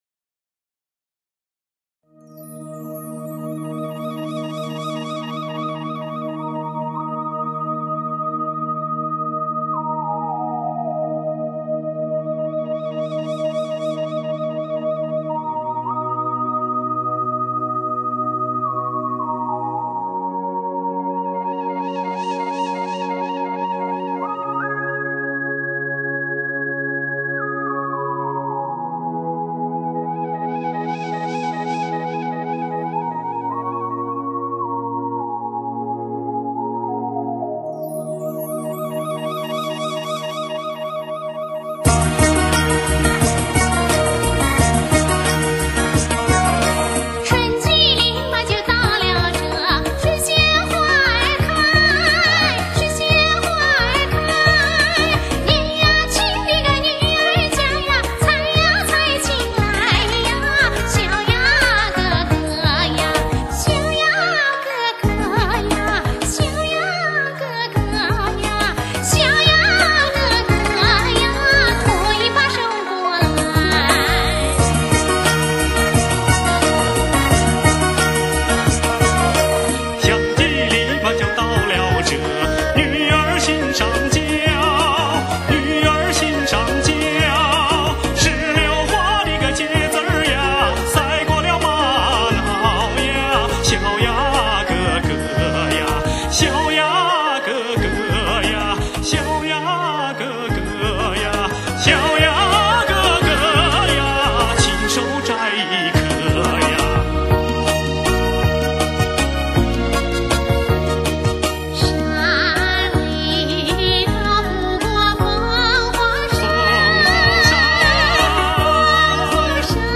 大西北民歌
再加上现代音乐的包装，令人耳目一新。
在第三小节，以3/4拍节的《园舞曲》载歌载舞的艺术表现，加之第四小段稍快的节奏，把歌曲的意境发挥得淋漓尽致!